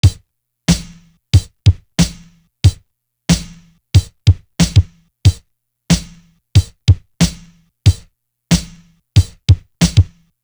Sun Drum.wav